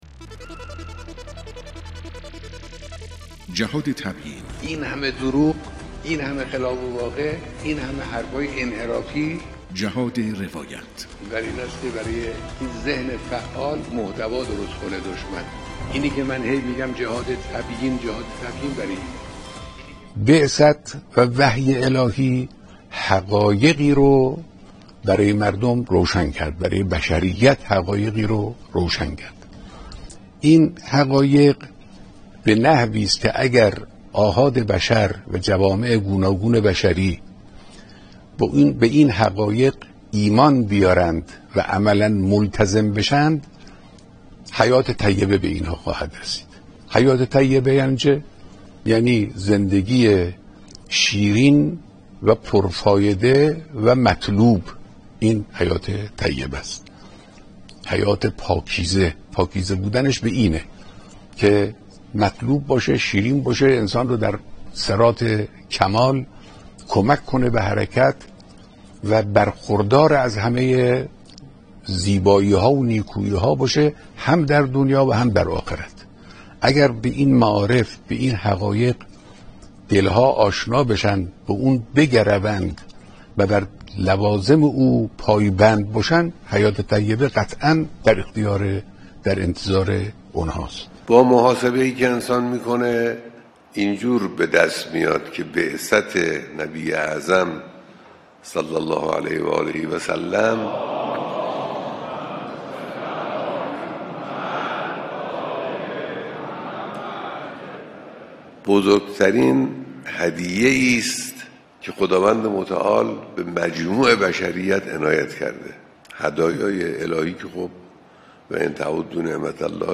بیانات مقام معظم رهبری